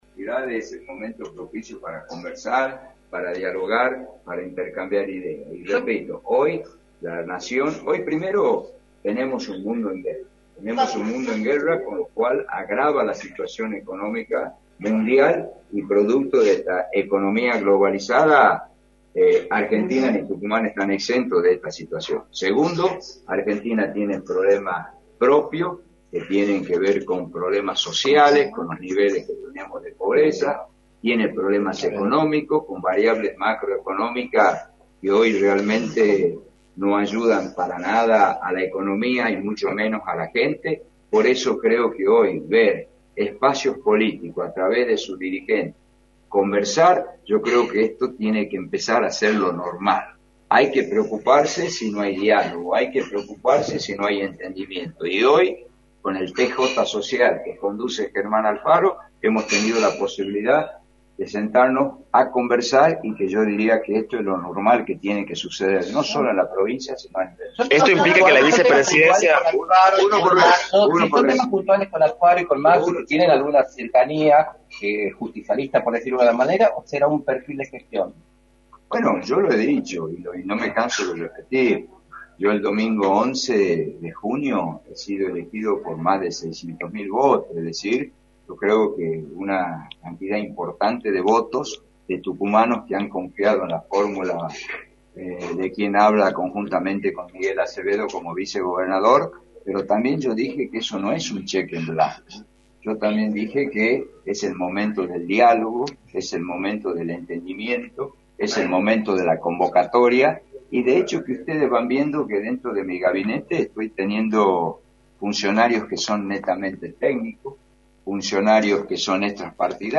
“El final es el momento propicio para conversar, para dialogar, para intercambiar ideas, hoy primero, tenemos un mundo en guerra, con lo cual esp agrava la situación económica mundial y Argentina y Tucumán están exentos de esta situación, segundo, Argentina tiene un problema propio que tiene que ver con problemas sociales, con los niveles que tenemos de pobreza, por lo que hoy tenemos que conversar, hoy con el PJS que conduce Germán Alfaro, hemos tenido la posibilidad de sentarnos a conversar y que yo diría que esto es lo normal que tiene que suceder” señaló Osvaldo Jaldo en entrevista para “La Mañana del Plata”, por la 93.9.